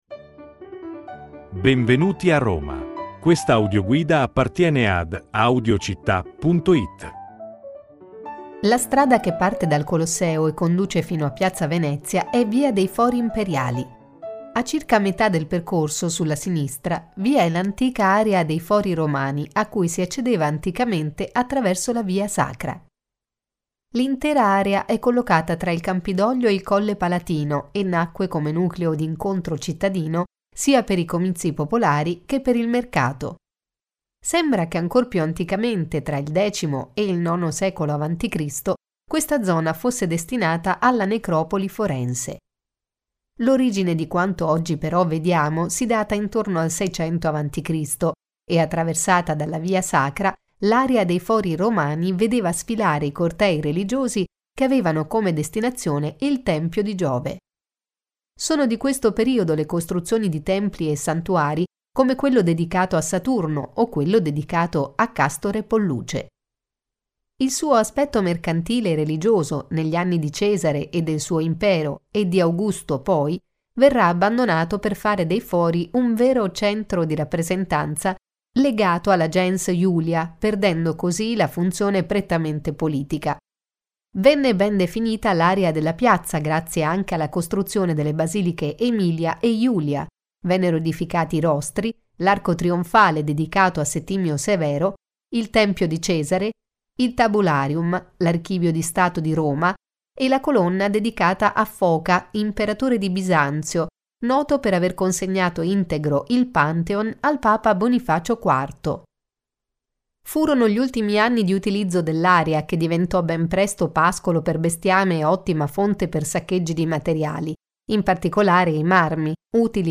Audioguida Roma – I Fori